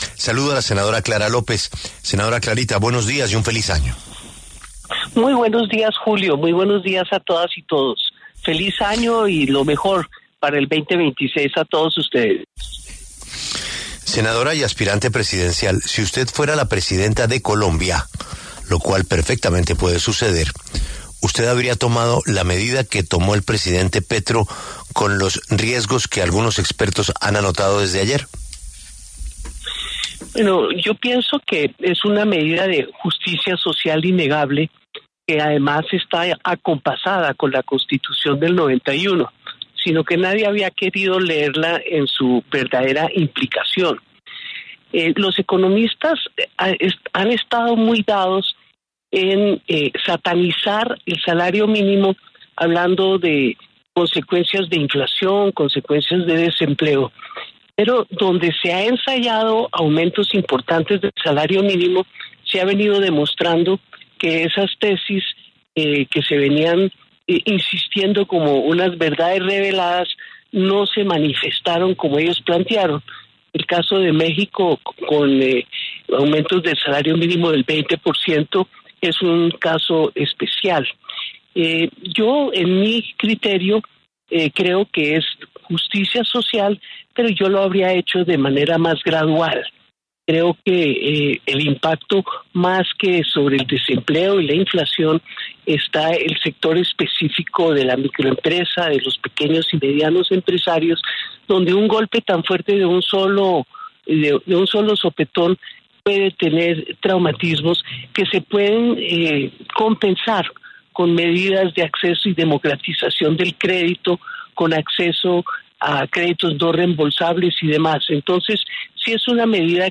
Clara López, senadora de la República y precandidata presidencial, pasó por los micrófonos de La W para hablar sobre el incremento del salario mínimo para 2026.